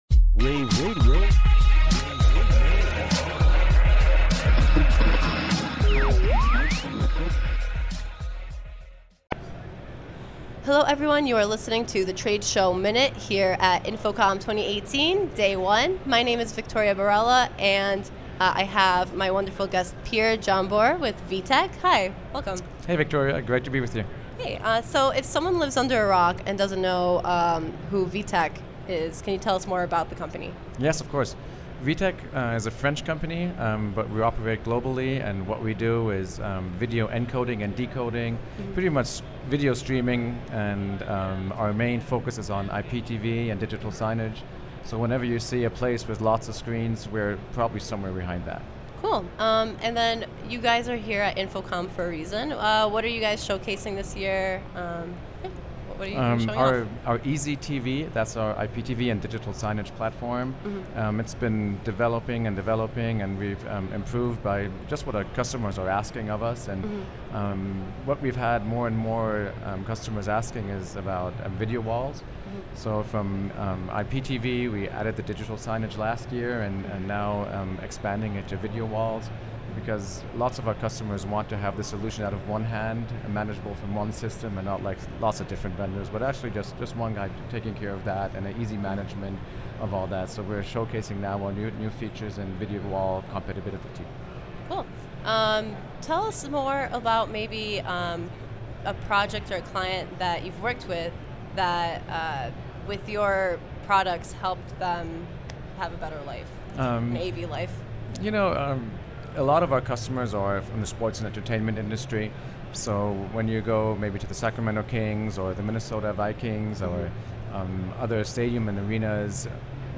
interviews
at InfoComm 2018.